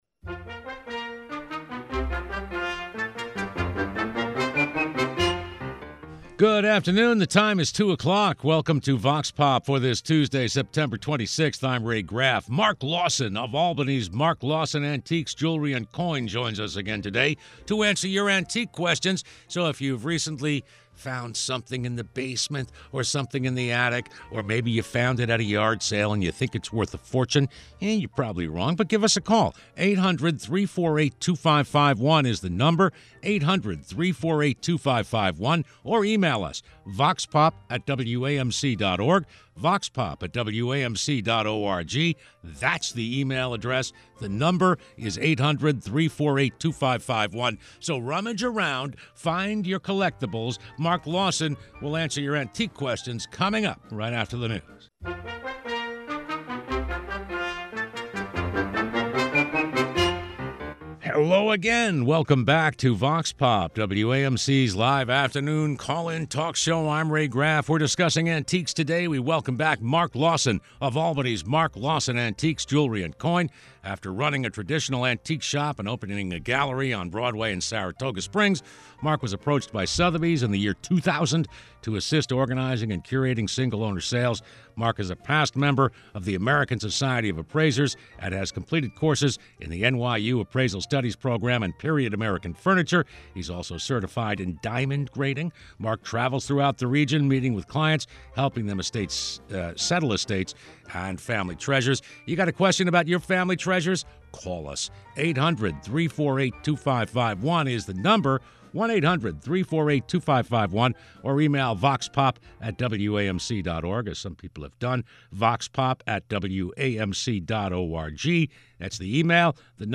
Vox Pop is WAMC's live call-in talk program.